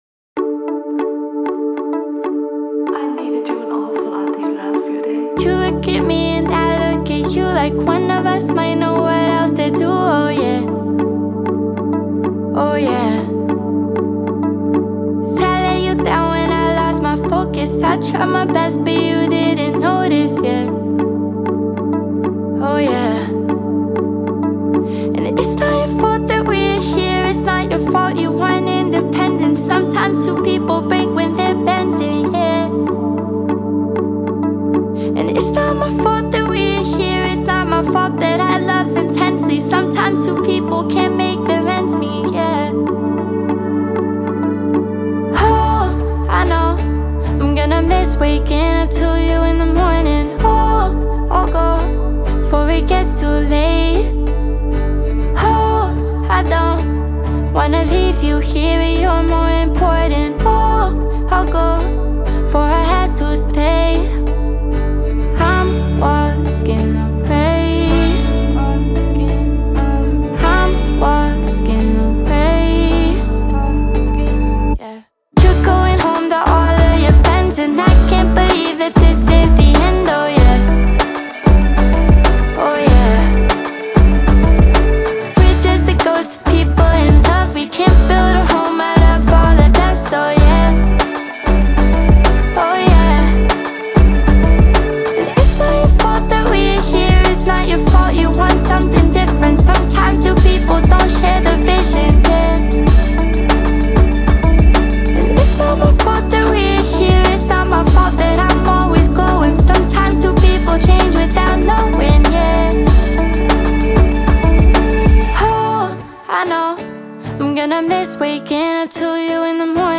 это эмоциональная песня в жанре инди-поп
Песня выделяется мелодичным звучанием и нежным вокалом